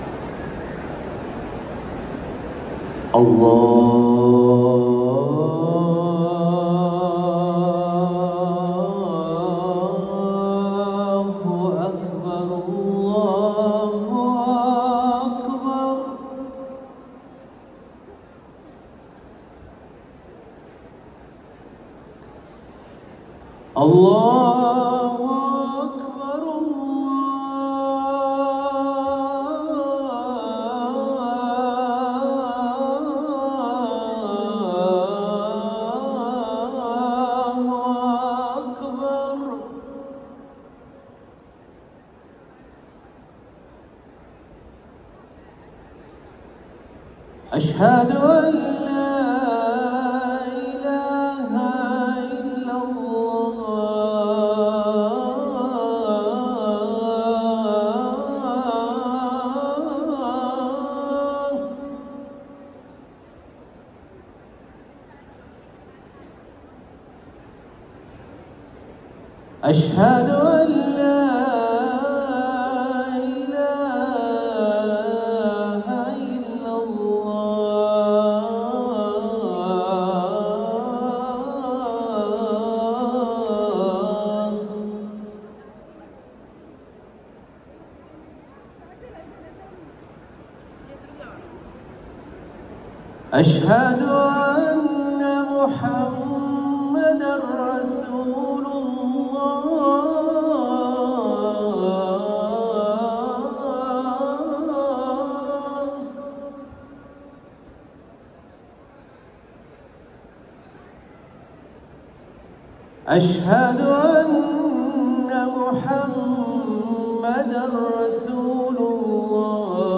أذان الفجر الأول